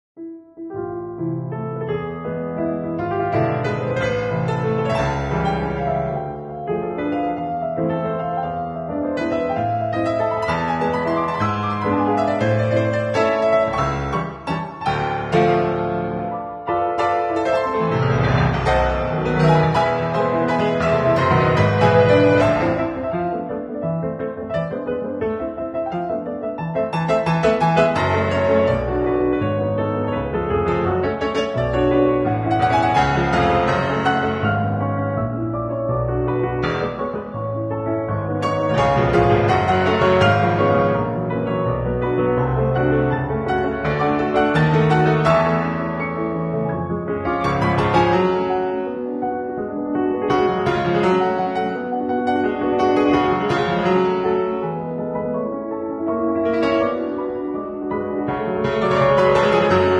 Waltz variation
“Happy birthday” motif 6/8 variation